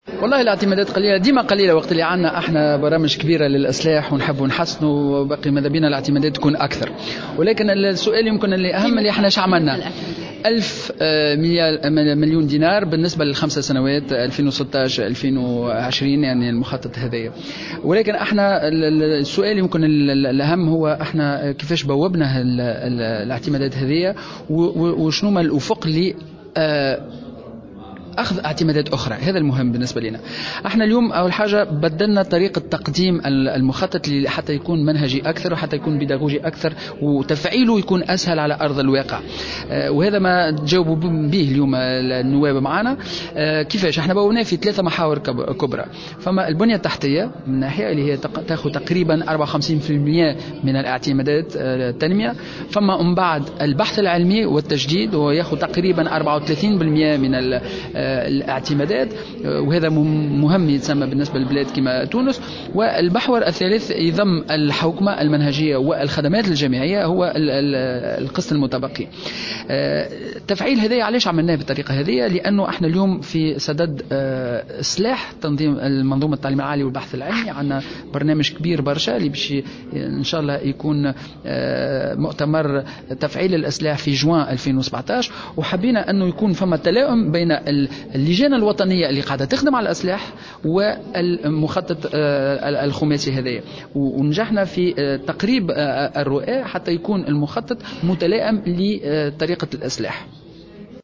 وأضاف خلبوس في تصريح لمراسلة الجوهرة اف ام، أن ميزانية الوزارة المقدرة بألف مليون دينار سيتم تقسيمها على 3 محاور، أولها البنية التحتية بنسبة 54 بالمائة، ثم البحث العلمي والتجديد بنسبة 34 بالمائة، ثم 12 بالمائة للحوكمة المنهجية والخدمات الجامعية.